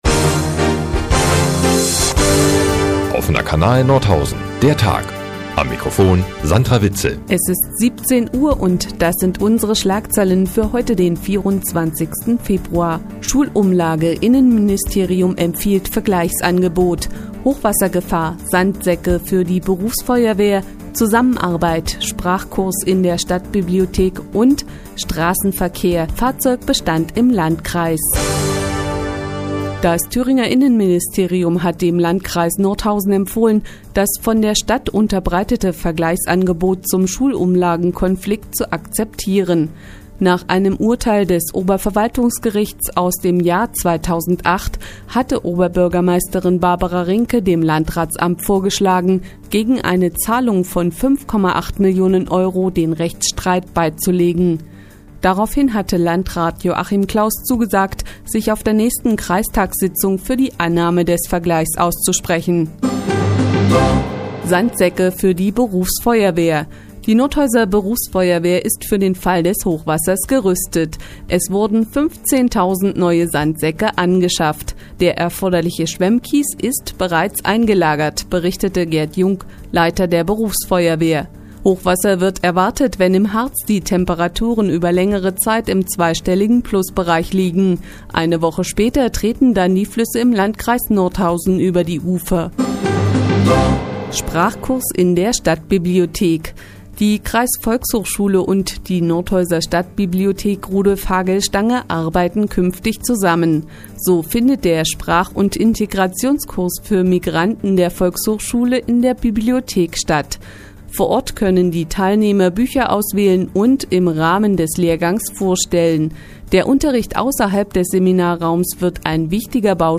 Die tägliche Nachrichtensendung des OKN ist nun auch in der nnz zu hören. Heute informieren wir Sie über Hochwasserschutz, eine politische Empfehlung, Sprachkurse und die Fahrzeugdichte im Landkreis.